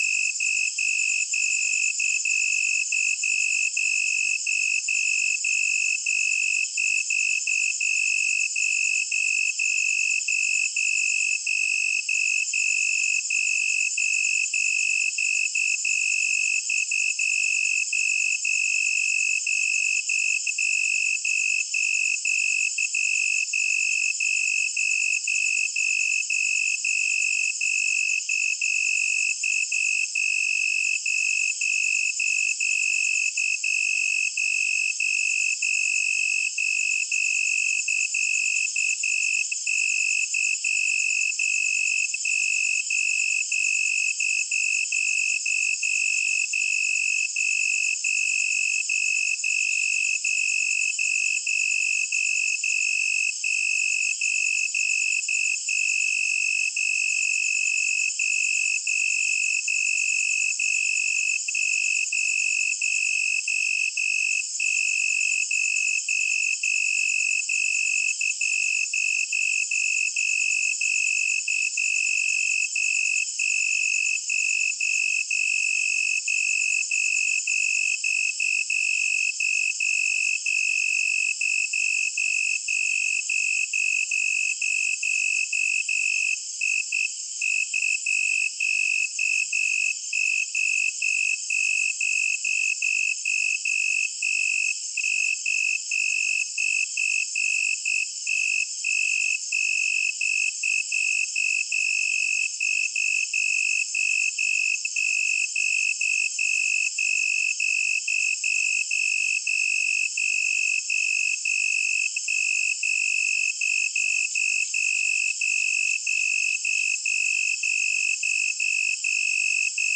Nature / Loops / Forest Night
forest-night-3.ogg